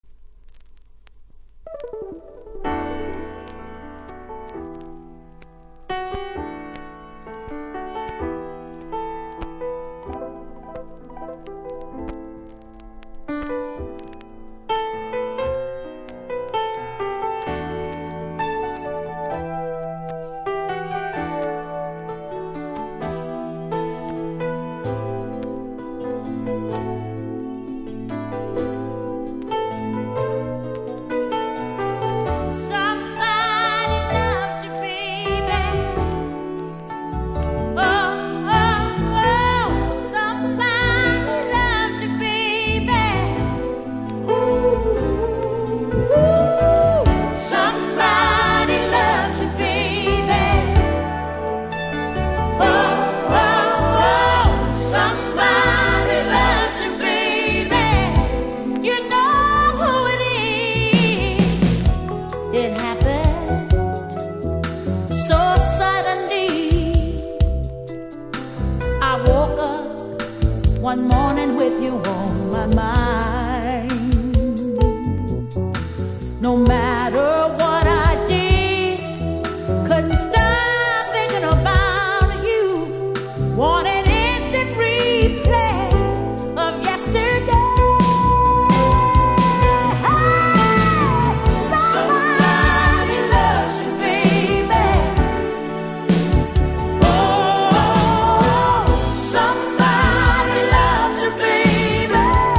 今回仕入れた３枚とも序盤に同じ様なノイズ感じますので試聴で確認下さい。